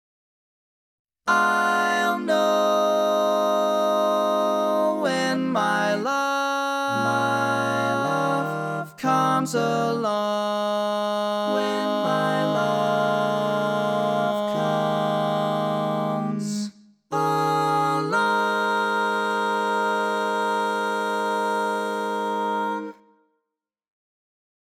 Key written in: A♭ Major
Type: Barbershop